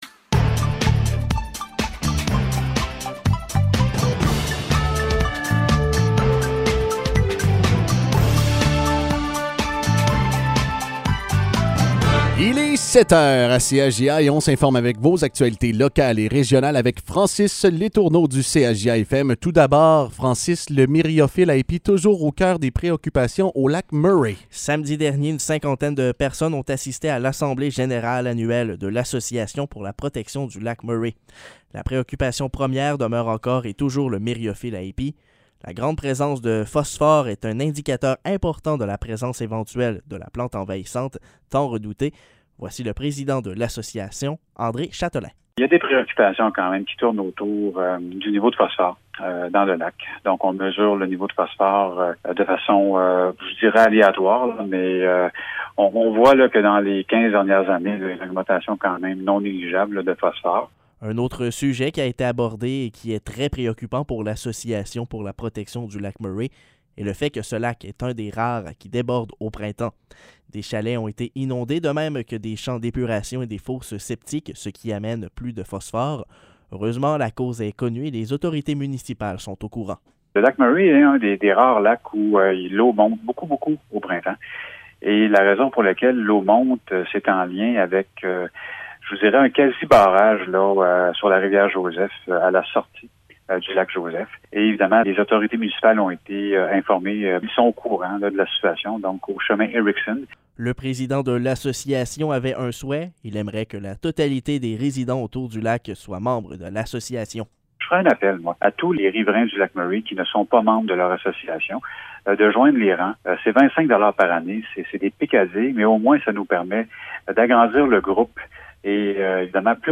Nouvelles locales – 8 août 2022 – 7 h